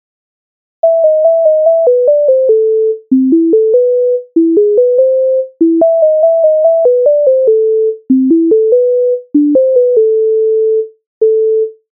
Написана у 1810 році багатель
ля мінор.